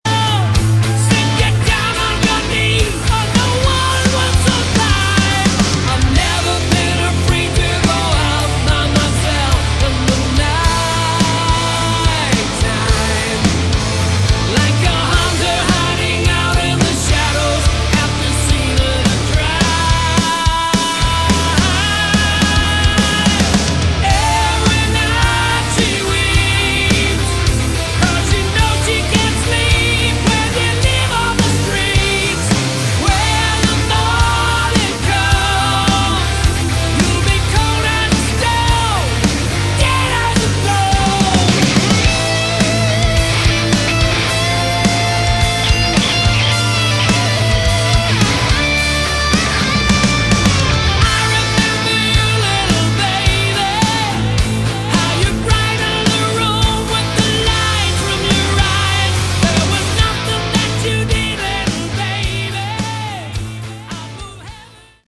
Category: Hard Rock
vocals
guitars
bass, keyboards
drums